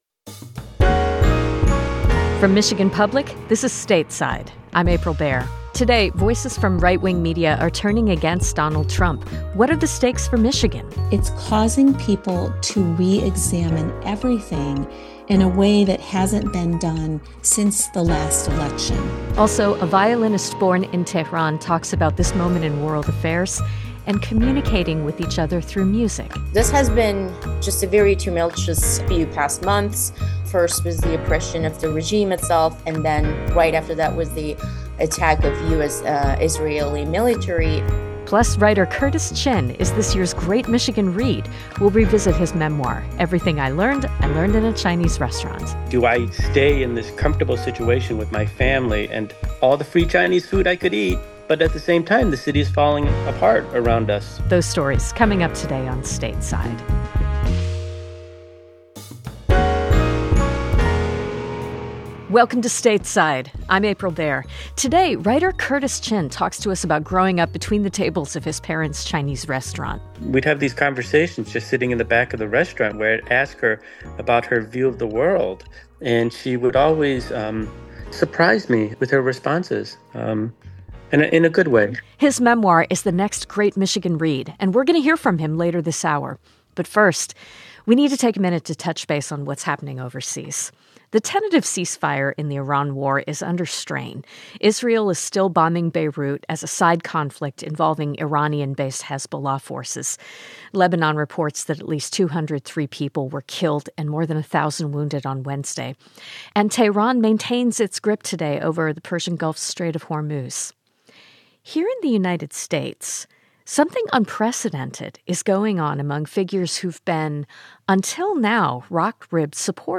Trump's break with key conservative voices over Iran. A conversation on creation with an Iranian-American artist. Plus, personal reflections on growing up Chinese-American in Detroit.